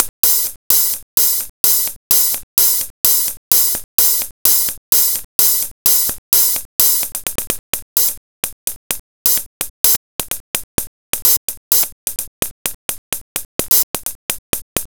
Session 11 - Hi-Hat.wav